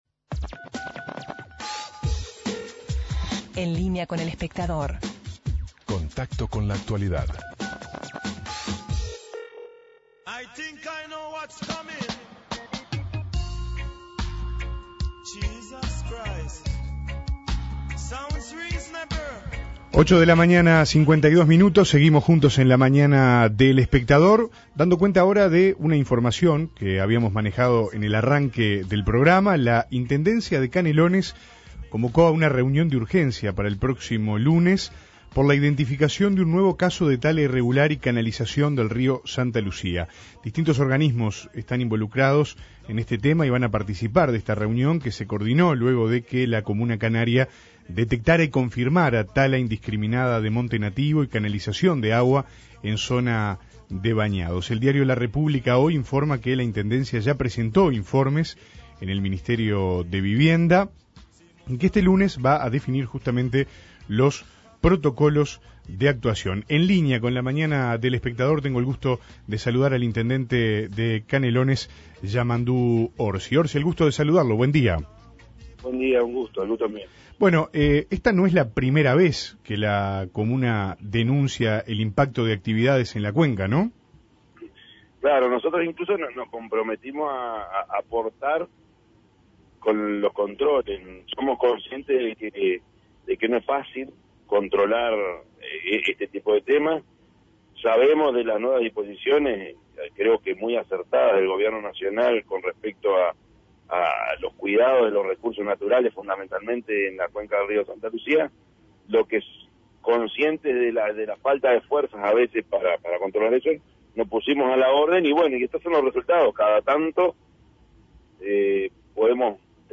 ENTREVISTA AL INTENDENTE DE CANELONES